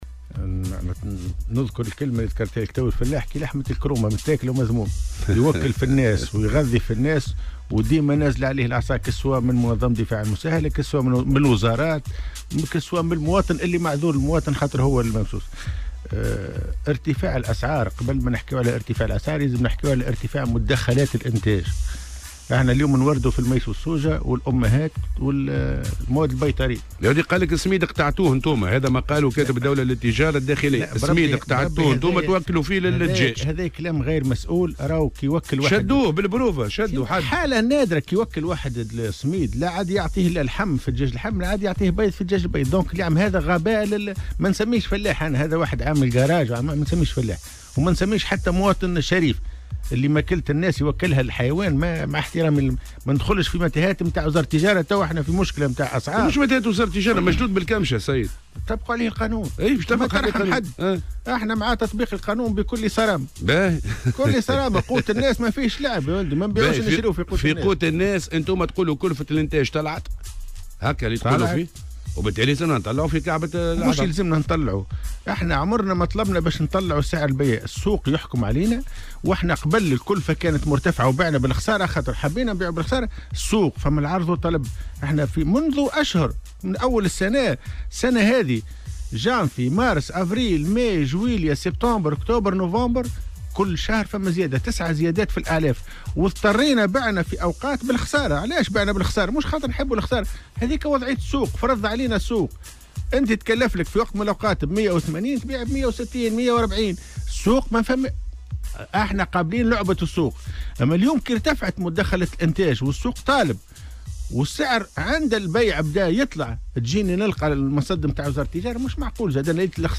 وأضاف في مداخلة له اليوم في برنامج "صباح الورد" على "الجوهرة أف أم" أنه لا يمكن إجبار المنتجين على بيع البيض بسعر أقل من كلفة الإنتاج، مؤكدا أن ذلك سيكبّدهم خسائر فادحة، وفق تعبيره. كما أوضح أيضا أنه يجب التحيين الآلي لكلفة الإنتاج ومراعاة مصلحة المربين في ظل ارتفاع أسعار العلف، نافيا ما تم تداوله بخصوص الاحتكار وإخفاء المنتجين للبيض.